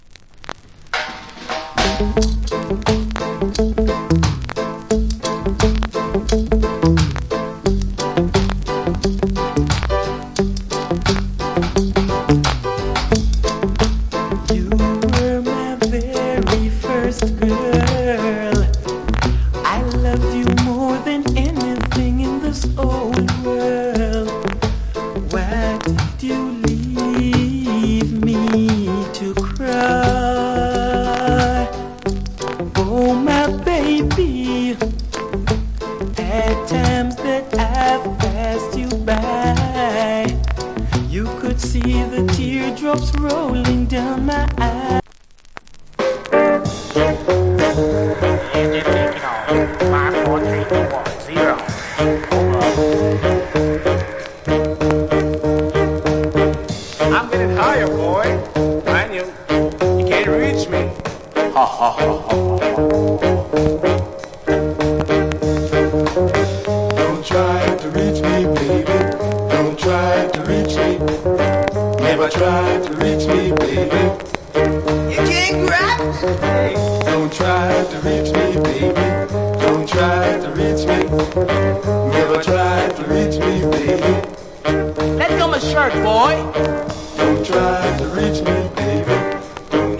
Rock Steady Vocal.